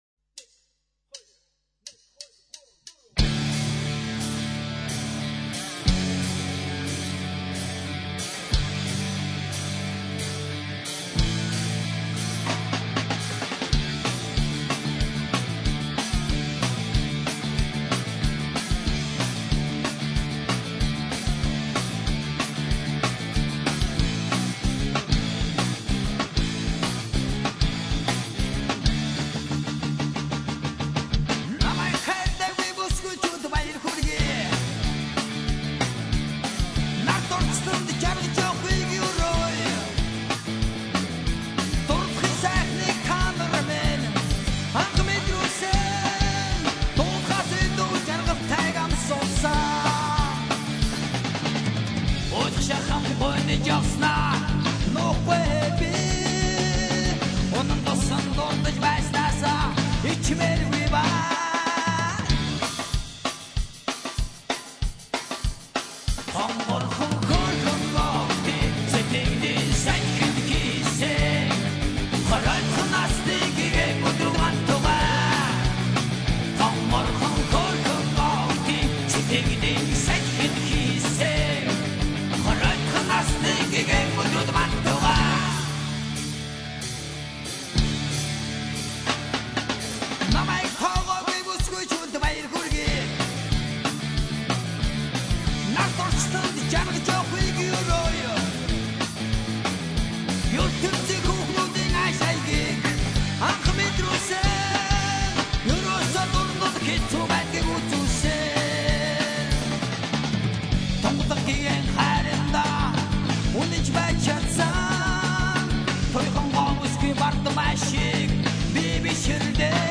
rockandroll.mp3